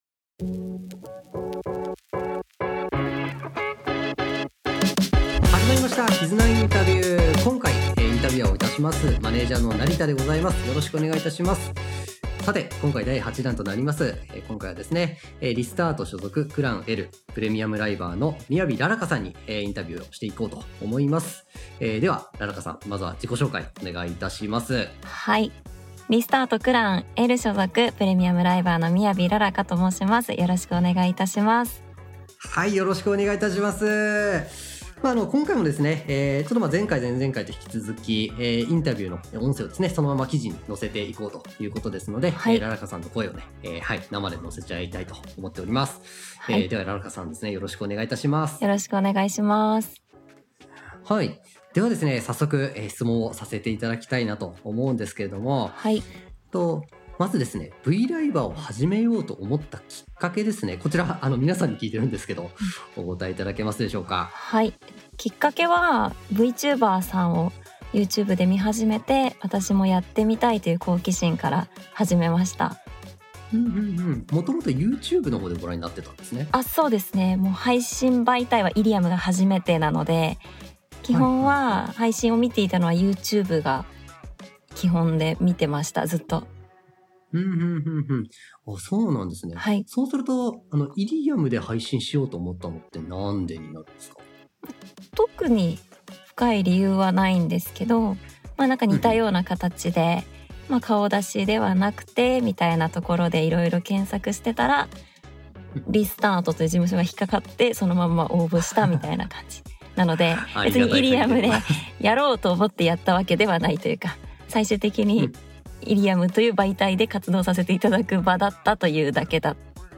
KIZUNAインタビュー